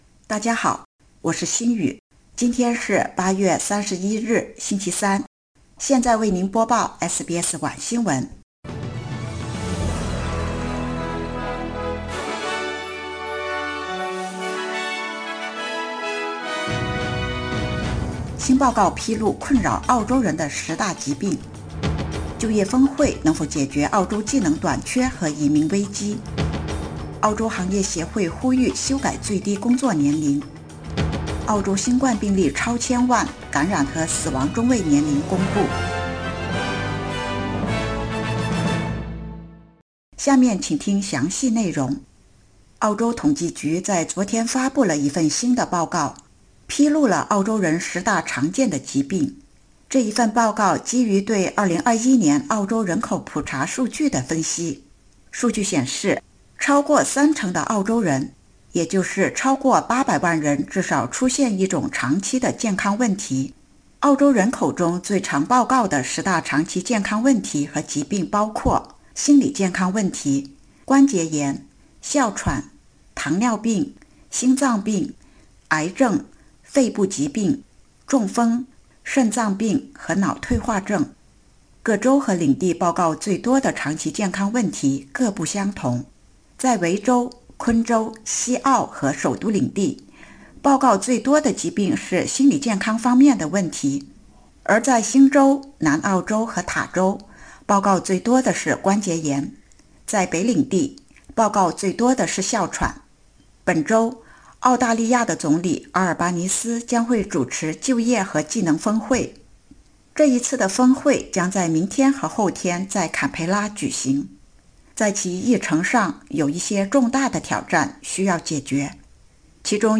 SBS Mandarin evening news Source: Getty / Getty Images